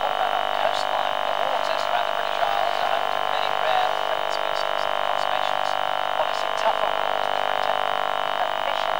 tv loop recording
I placed a loop receiver inside the loop and could hear the news through the loop receiver.  But when I recorded through the loop receiver (records the modulating magnetic signal) the sound was difficult to locate in software “audacity” as it was buried within the noise of the signal but clearly heard through a speaker and this is the mp3 recording below.  If you sit in the centre of the loop see if you can hear the modulating tinnitus sound.